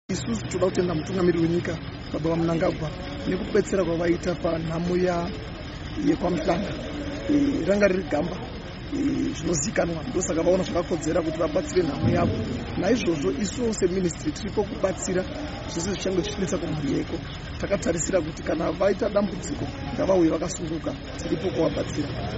Mazana evanhu vabva kumatunhu akasiyana siyana enyika vanosanganisira makurukota ehurumende, vezvemitambo nevaimbi, vanagwenyambira navanamuzvinabhizimisi vaungana paBulawayo Amphitheater muguta reBulawayo pamunamato wekuonekana nanyanduri aive nemukurumbira uye ari mukuru weAmakhosi Theatre, Cont Mhlanga.
Mumwe ataurawo pagungano iri mutevedzeri wegurukota rezvemitambo netsika, VaTino Machakaire, avo vatenda hurumende nekubatsira kwayakaita mhuri yaMhlanga nekupa rutsigiro rwekuchengetwa kwake.